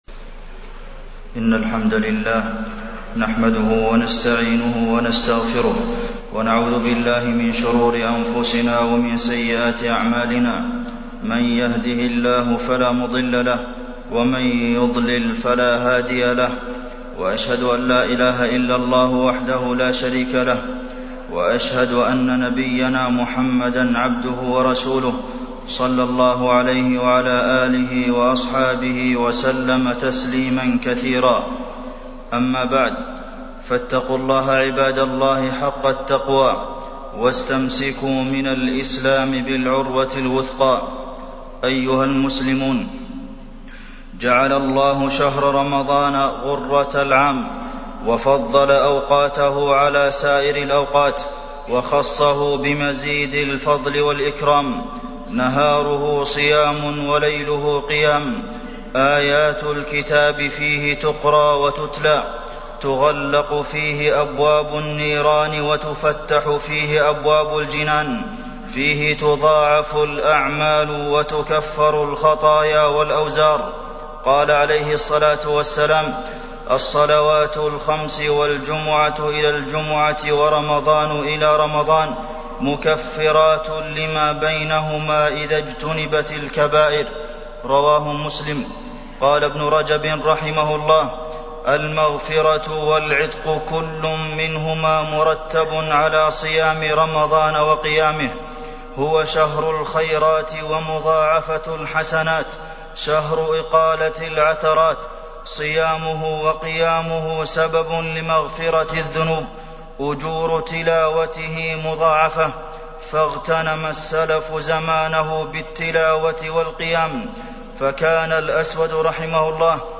تاريخ النشر ١٣ رمضان ١٤٢٧ هـ المكان: المسجد النبوي الشيخ: فضيلة الشيخ د. عبدالمحسن بن محمد القاسم فضيلة الشيخ د. عبدالمحسن بن محمد القاسم شهر رمضان